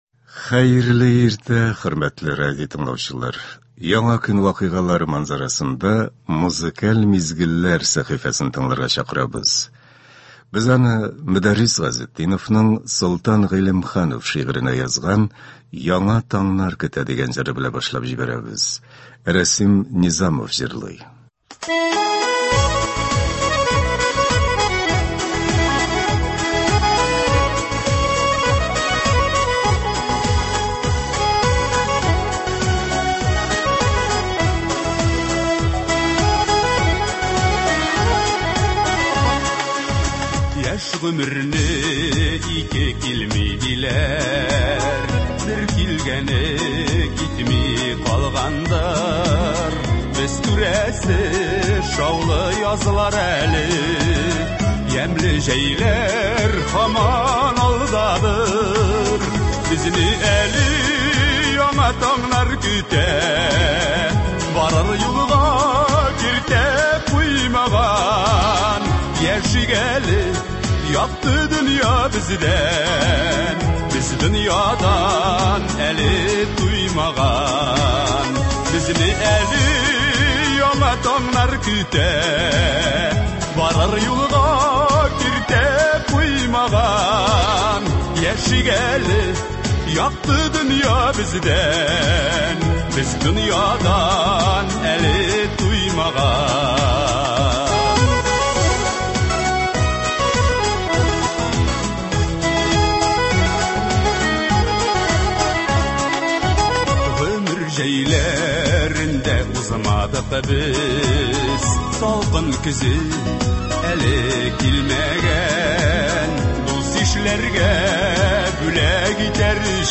Без сезнең өчен, хөрмәтле радиотыңлаучыларыбыз, яхшы кәеф, күңел күтәренкелеге бирә торган концертларыбызны дәвам итәбез.